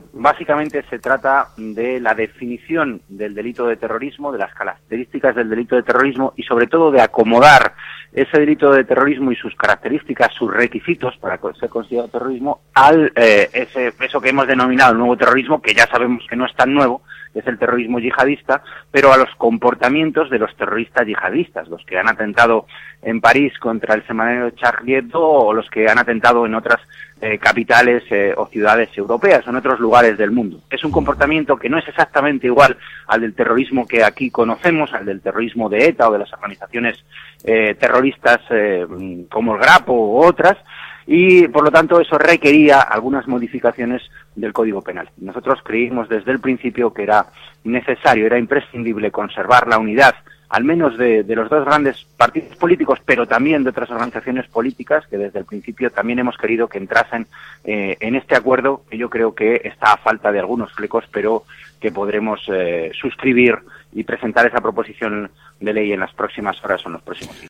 Fragmento de la entrevista con Carlos Herrera en Onda Cero el 29/1/2015 en el que Antonio Hernando explica que el acuerdo contra el terrorismo yihadista está cerca